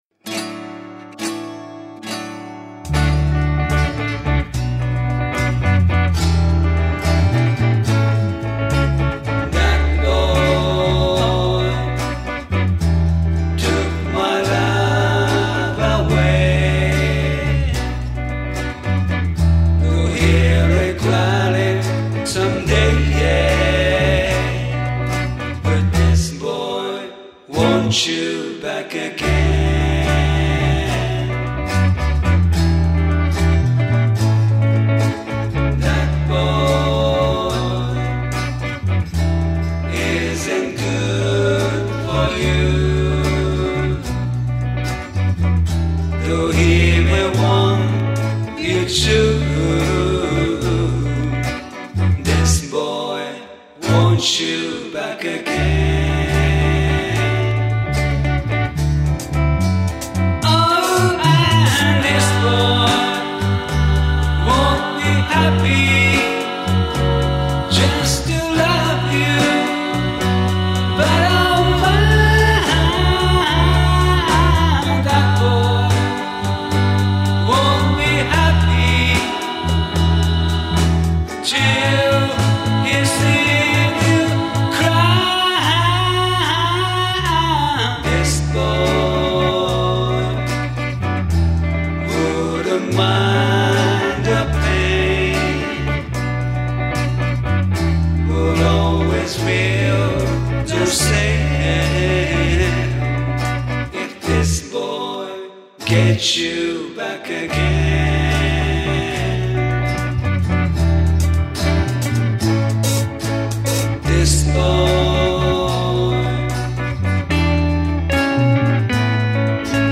かなり昔に録音し出来も今ひとつだったので、再録してみました。
特徴としてはボーカルのリバーブがやや深め、リンゴのドラムもバランスが小さめです。
そしてなんと言っても美しい三部のハーモニー！！